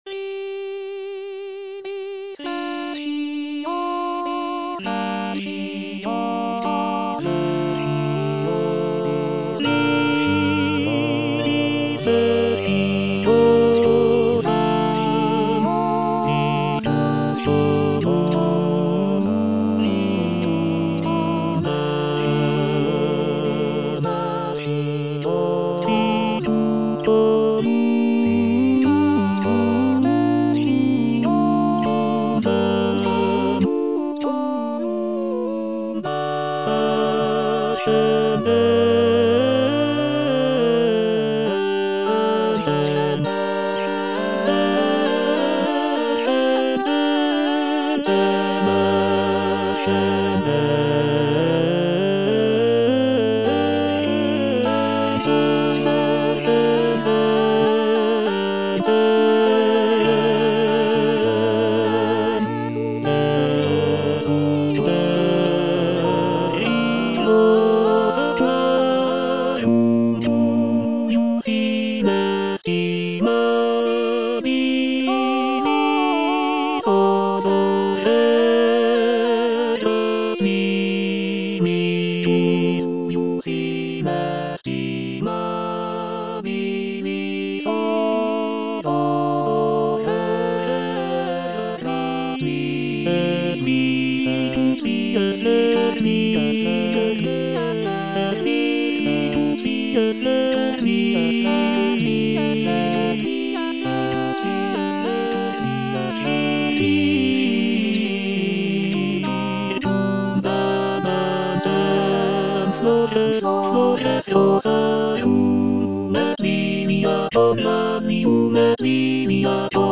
R. ALEOTTI - VIDI SPECIOSAM Tenor II (Emphasised voice and other voices) Ads stop: auto-stop
"Vidi speciosam" is a choral motet composed by Raffaella Aleotti, an Italian nun and composer from the late Renaissance period. The piece is written for four voices and is known for its beautiful and expressive melodies.